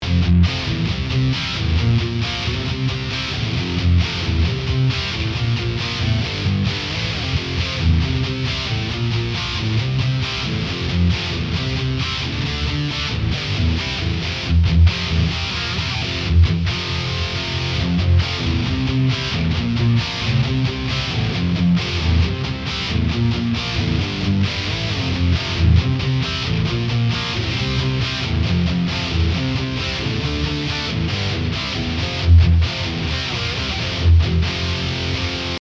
��������� ��������� 9V vs 12V | AMT R1 + Heater